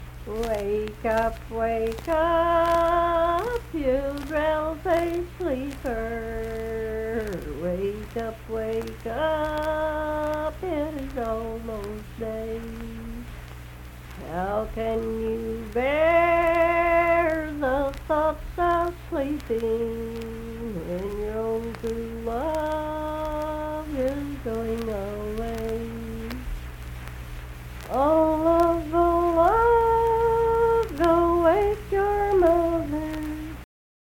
Unaccompanied vocal music performance
Verse-refrain 1(4).
Voice (sung)